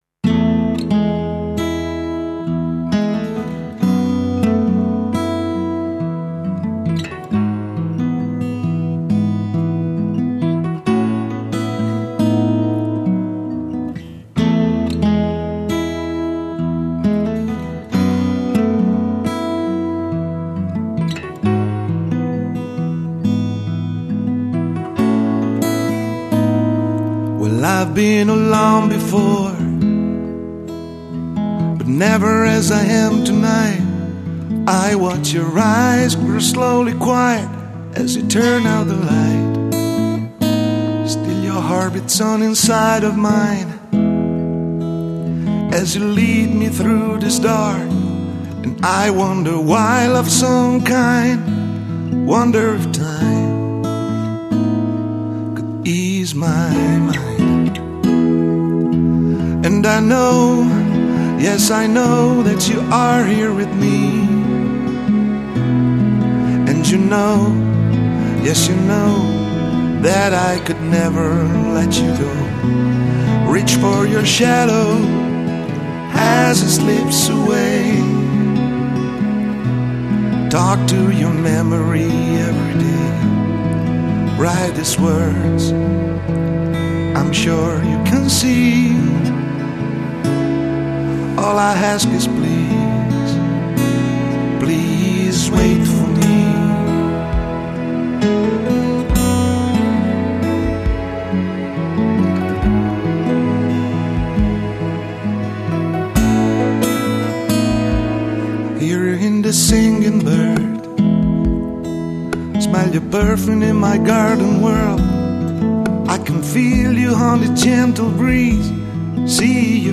Vocals - Acoustic Guitars
Keyboards and Drums Programming